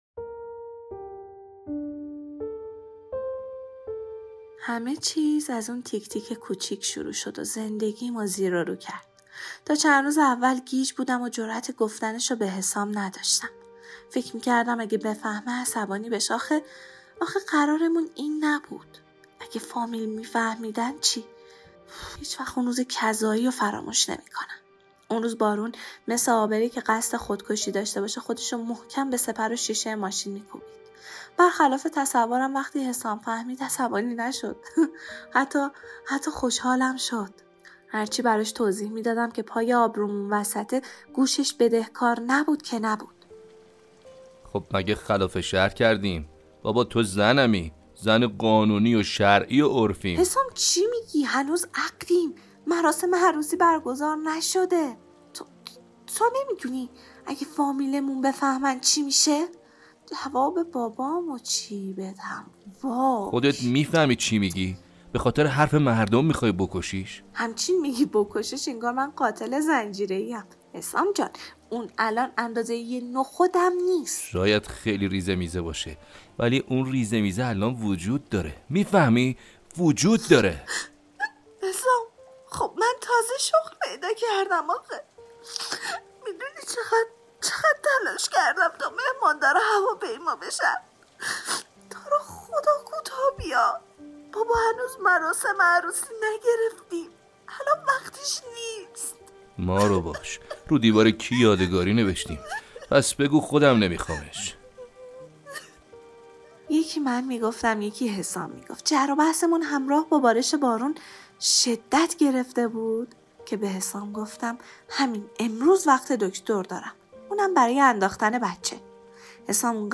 سقط جنین داستان صوتی